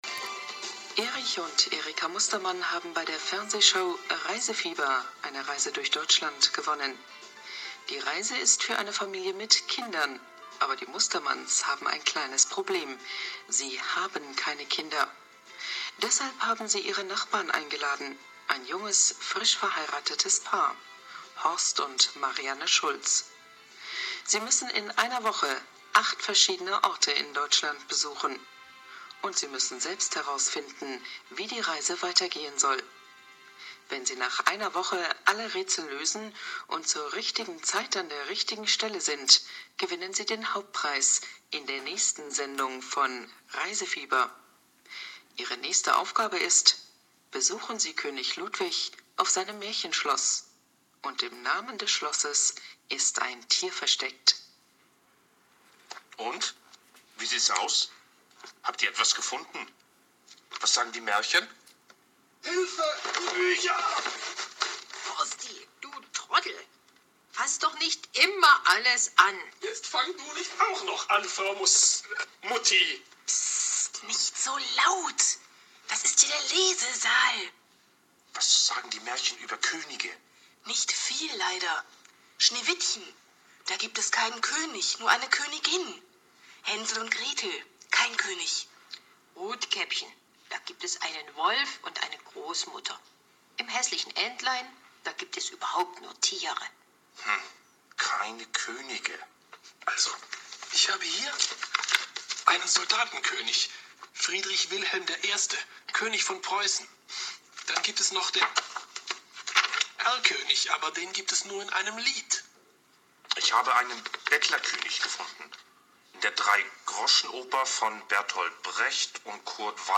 Innenakustik, Bibliothek.
Man hört ein paar Dutzend Bücher aus dem Regal fallen.